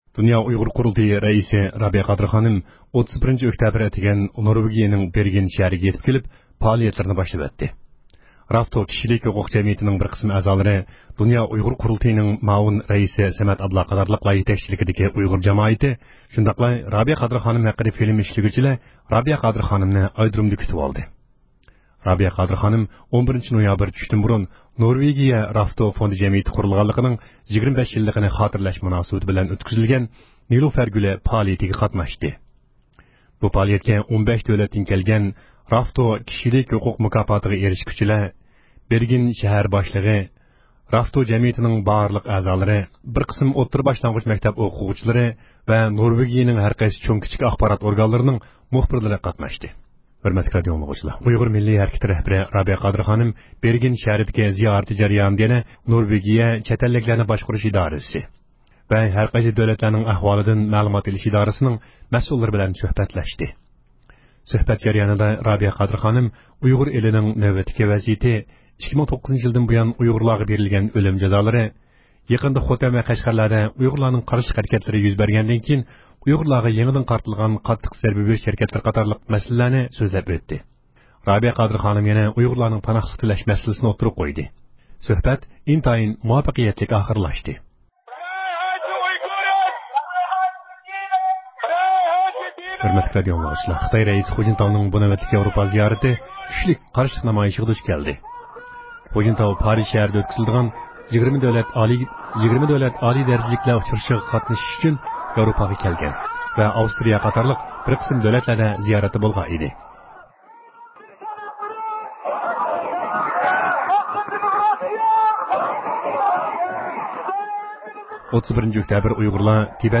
ھەپتىلىك خەۋەرلەر (29-ئۆكتەبىردىن 4-نويابىرغىچە) – ئۇيغۇر مىللى ھەركىتى